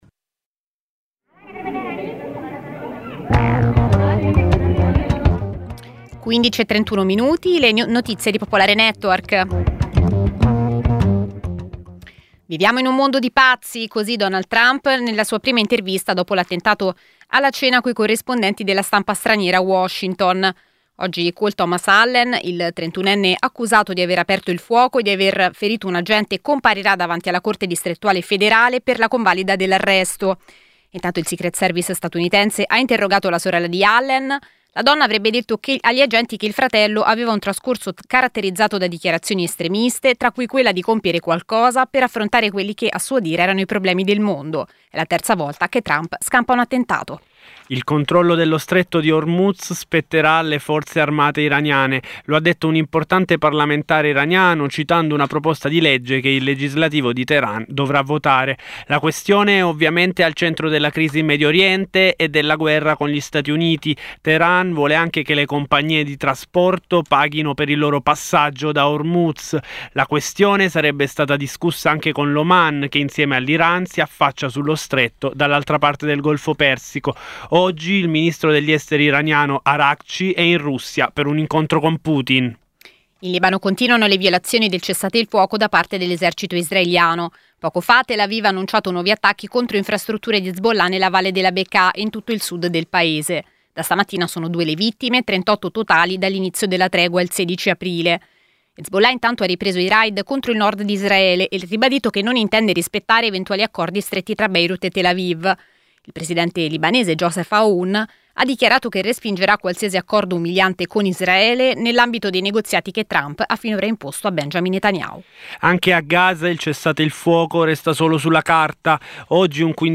Edizione breve del notiziario di Radio Popolare.